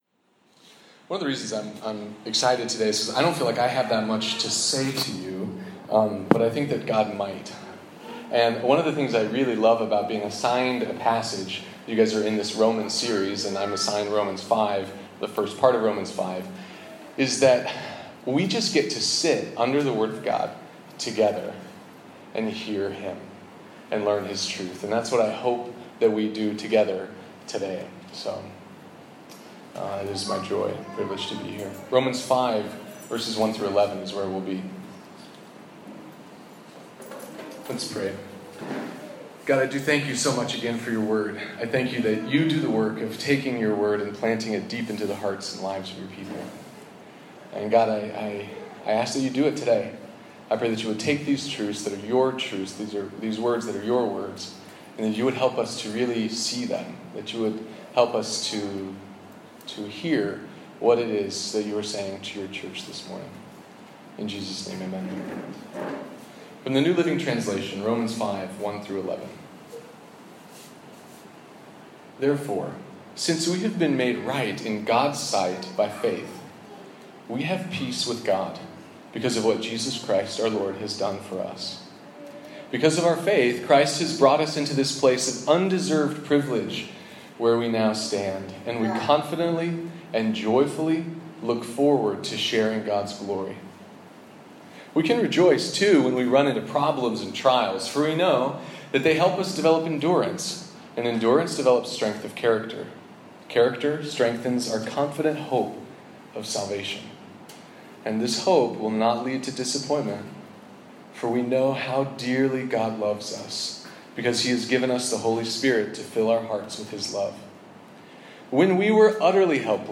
About the God who restores, gushes, and calls us friends. Recorded at Second Mile Church, Phnom Penh Cambodia, November 2017.